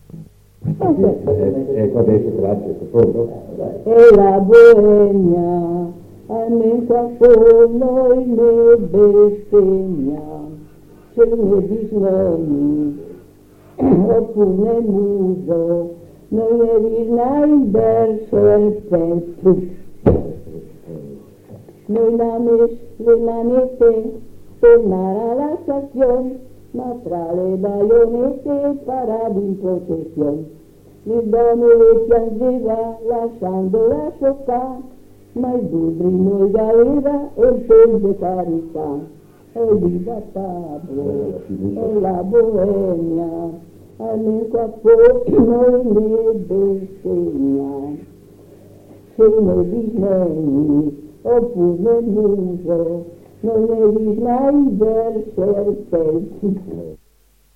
7 giugno 1978». 1975. 1 bobina di nastro magnetico.